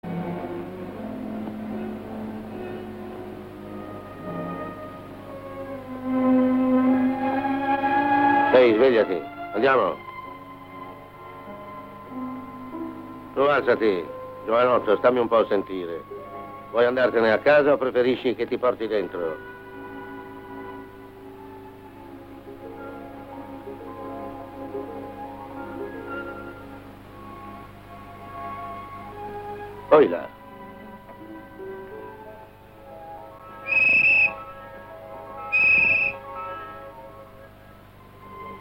voce di Camillo Pilotto nel film "Frankenstein contro l'Uomo lupo", in cui doppia Charles Irwin.